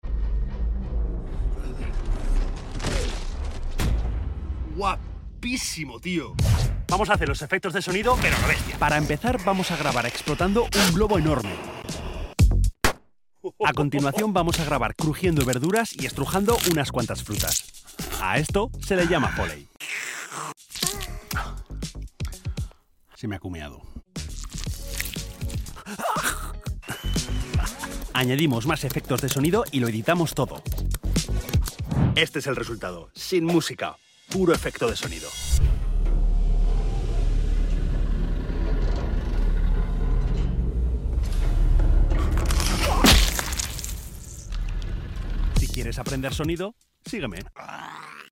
Diseño de sonido de esta sound effects free download
👀 Para hacer los efectos de sonido he usado 3 habilidades que hay que tener si quieres ser un Sound Designer Profesional 💪🏼🎧 Las 3 habilidades: 1 ✂ Edicón de sonido 2 🍋 Foley 3 🎚 Mezcla Si consigues dominar estas habilidades las cosas que podrás hacer son ilimitadas!